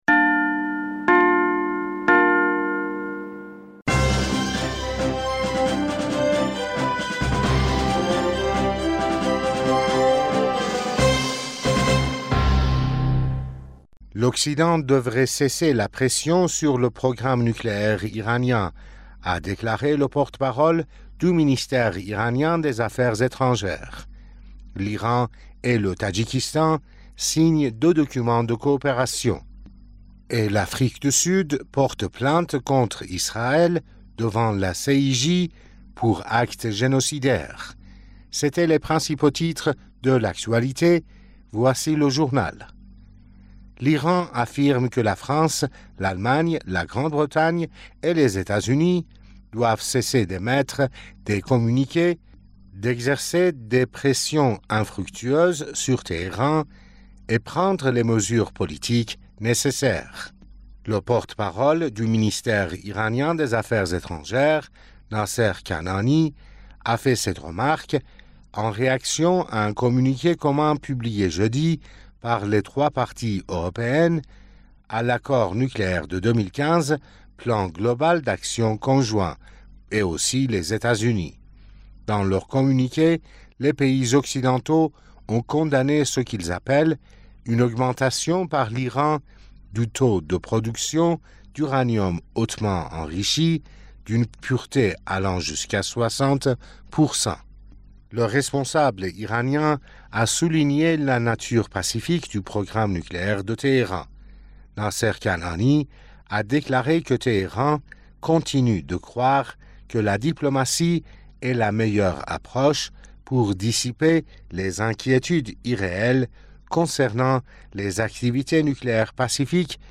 Bulletin d'information du 30 Decembre 2023